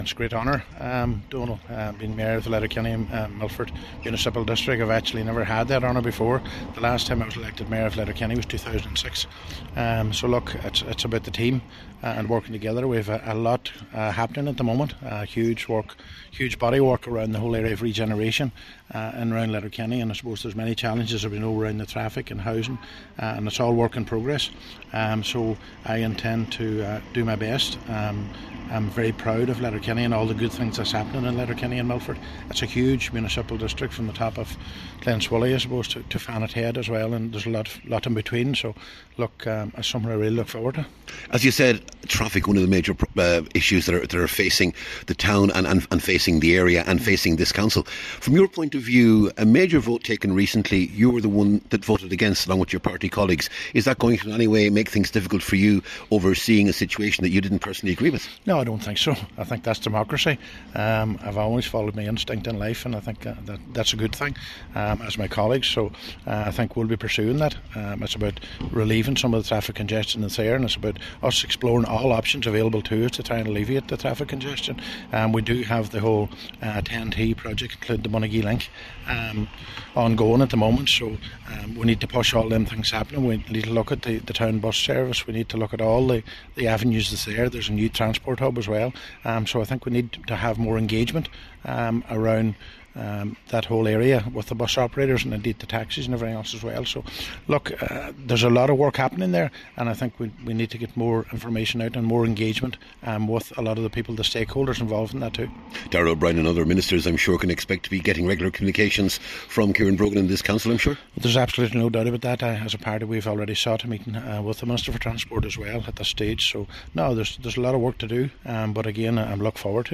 Speaking to Highland Radio News after his election, Cllr Brogan said addressing transport issues will be a major priority, and he and the council will be very vocal in pursuing the Bonagee Link and the Northern and Southern Relief Roads.